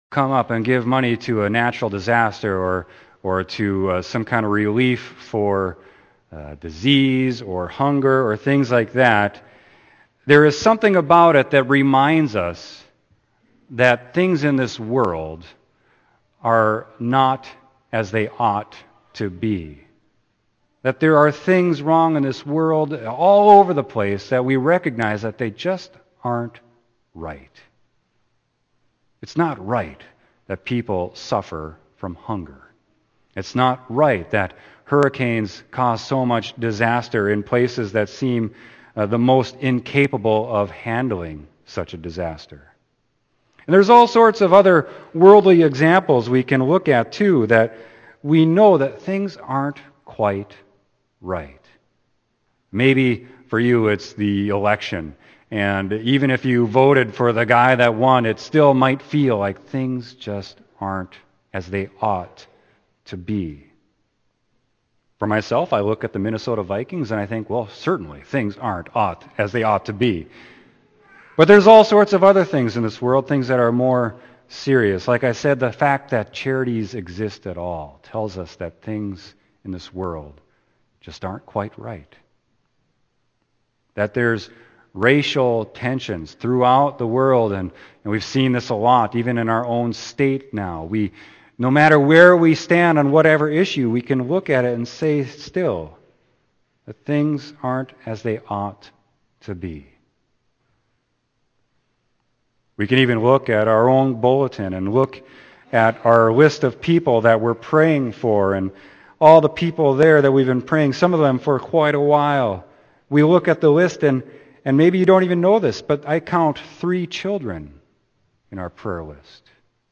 Sermon: Colossians 1:11-20